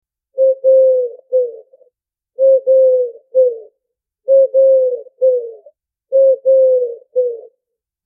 Modele AI generują efekty dźwiękowe na podstawie wprowadzonego promptu.
Oto kilka efektów dźwiękowych, które stworzyłem, wraz z ilustracjami przedstawiającymi ich źródła.
Sierpówka
dove.mp3